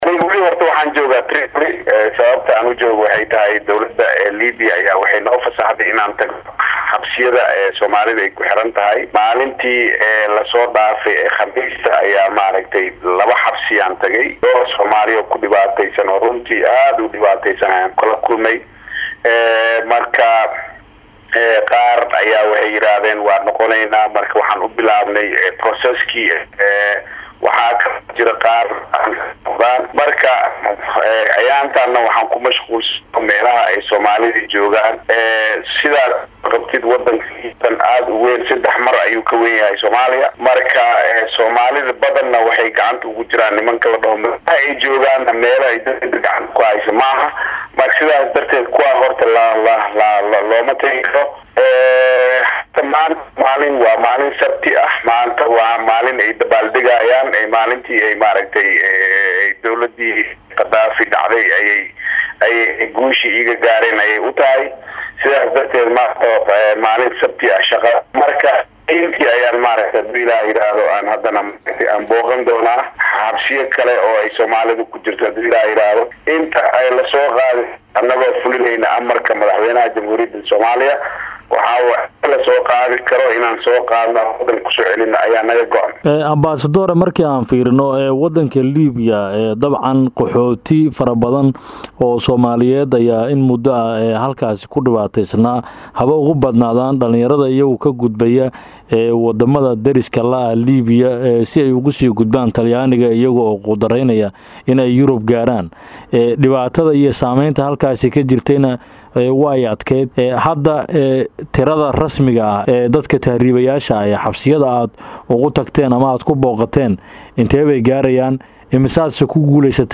Dhageyso Wareysi lala yeeshay Danjiraha Soomaaliya ee Midowga Yurub Ambasatoor Cali Saciid Fiqi.
waraysi-Ampsador-cali-saciid-faqi-danjiraha-soomaaliya-Midawga-Yurub-.mp3